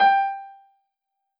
piano-ff-59.wav